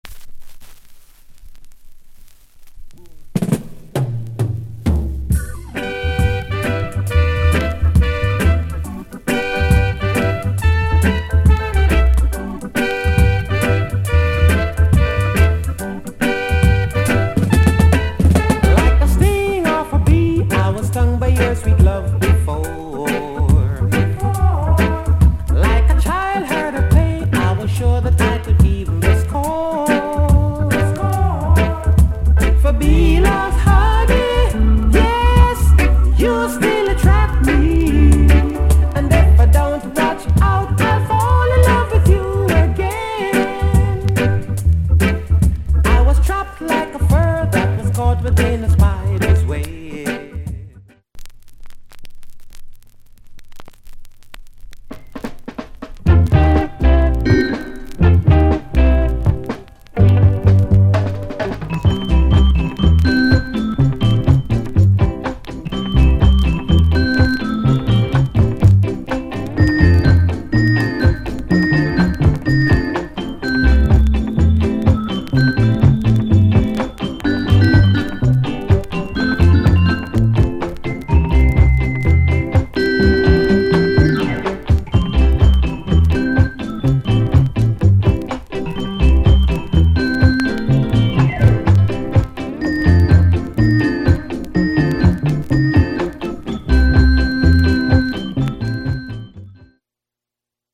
Genre Reggae70sMid / [A] Male Vocal Group Vocal [B] Inst